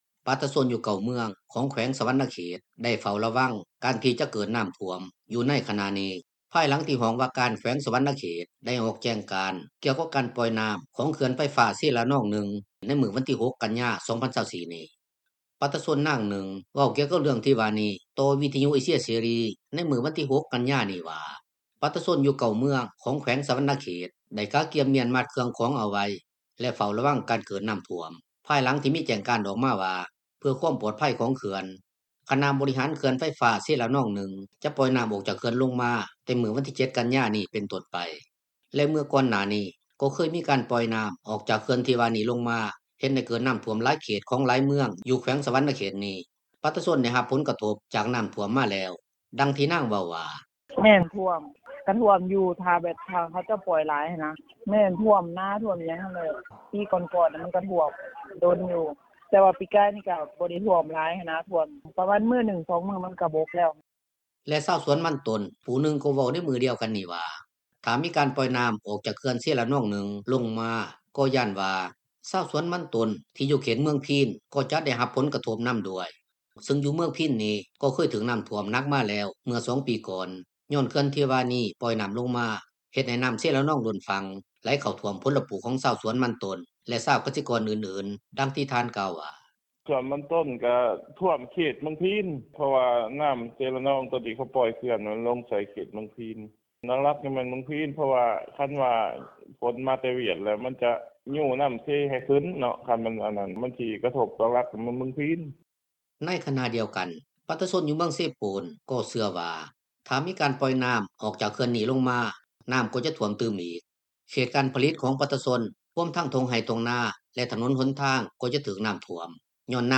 ປະຊາຊົນນາງນຶ່ງ ເວົ້າກ່ຽວກັບເລື່ອງທີ່ວ່ານີ້ ຕໍ່ວິທຍຸເອເຊັຍເສຣີ ໃນມື້ວັນທີ 6 ກັນຍານີ້ວ່າ ປະຊາຊົນຢູ່ 9 ເມືອງຂອງແຂວງສະຫວັນນະເຂດ ໄດ້ກະກຽມມ້ຽນມັດເຄື່ອງຂອງເອົາໄວ້ ແລະເຝົ້າລະວັງການເກີດນໍ້າຖ້ວມ ພາຍຫຼັງທີ່ມີແຈ້ງການອອກມາວ່າ ເພື່ອຄວາມປອດໄພຂອງເຂື່ອນ ຄະນະບໍລິຫານເຂື່ອນໄຟຟ້າເຊລະນອງ 1 ຂອງຈີນ ຈະປ່ອຍນໍ້າອອກຈາກເຂື່ອນລົງມາ ແຕ່ມື້ວັນທີ 7 ກັນຍານີ້ເປັນຕົ້ນໄປ.